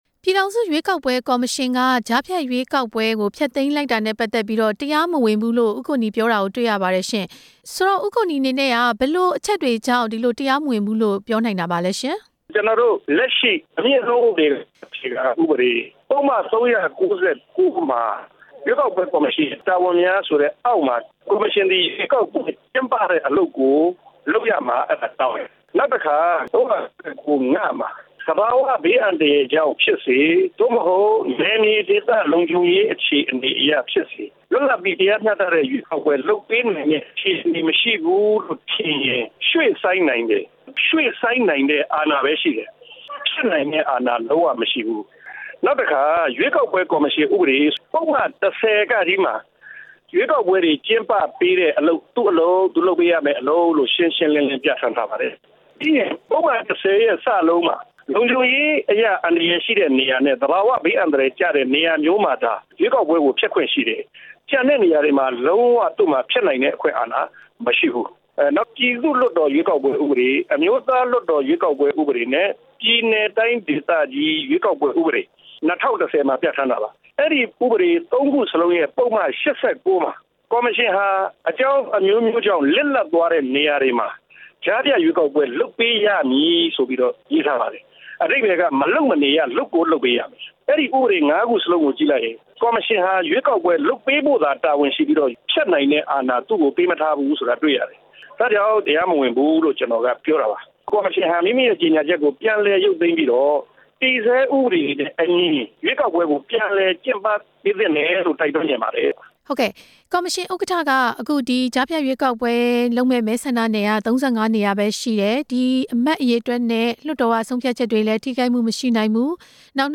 ရှေ့နေဦးကိုနီနဲ့ မေးမြန်းချက်